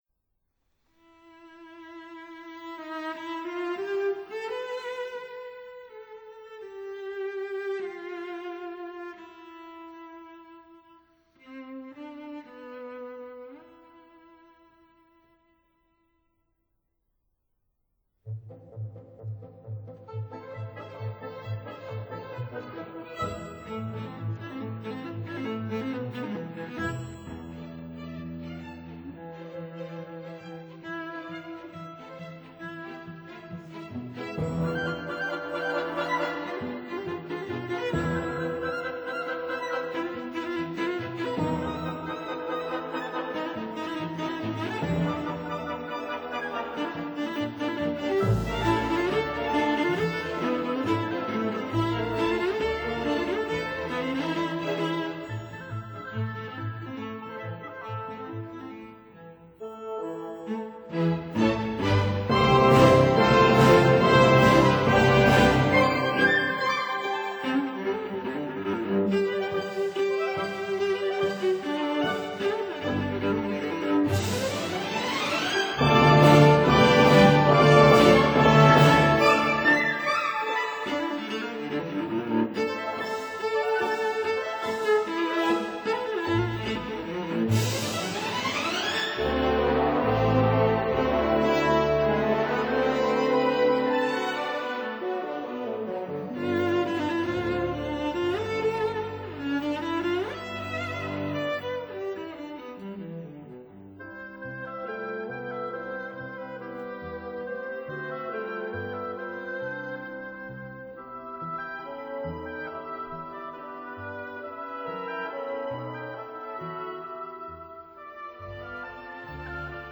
arranged for cello and orchestra